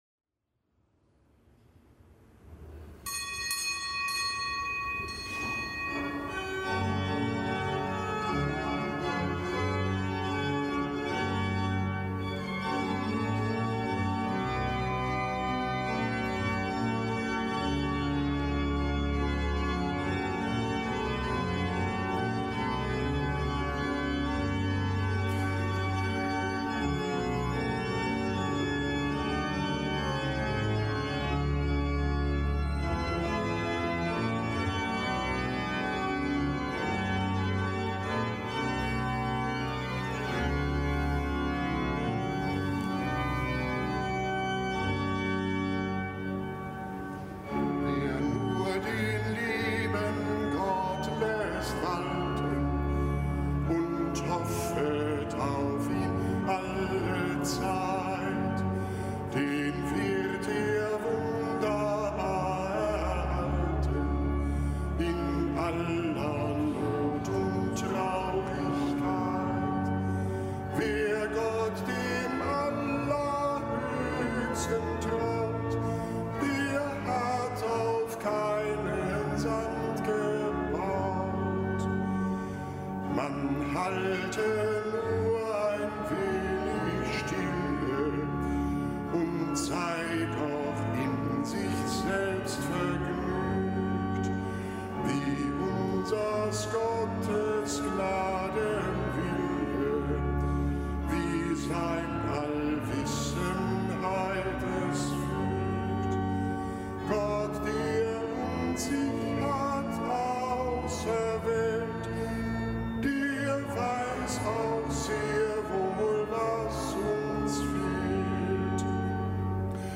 Kapitelsmesse am Donnerstag der zweiten Woche im Jahreskreis